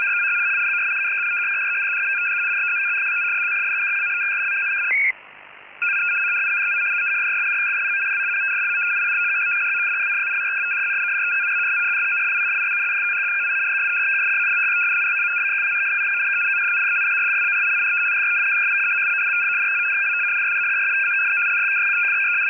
MCVFT-systems (Multichannel VFT)
3 x FEC-200 Bd